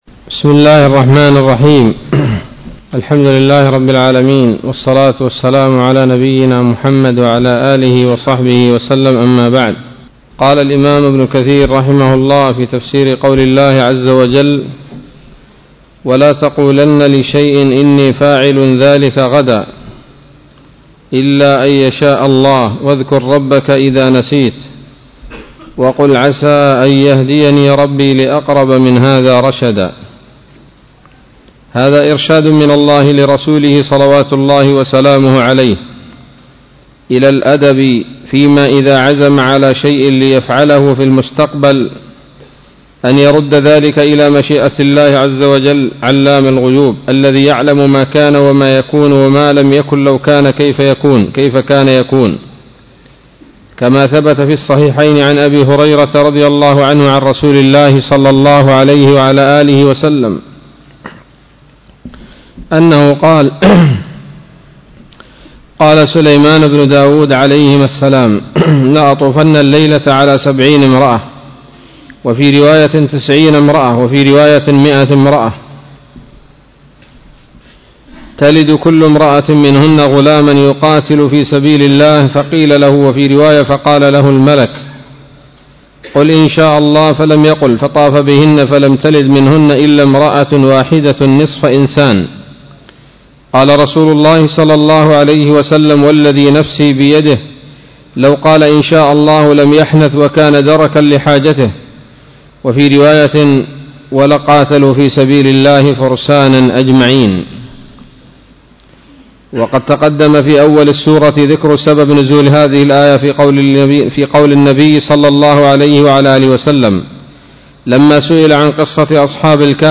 الدرس السادس من سورة الكهف من تفسير ابن كثير رحمه الله تعالى